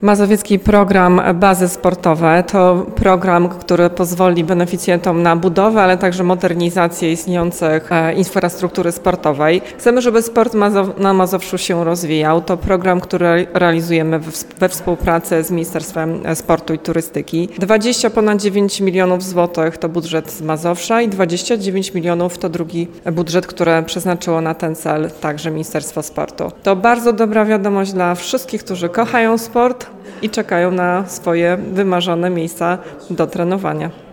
– informuje Anna Brzezińska, członkini zarządu województwa mazowieckiego.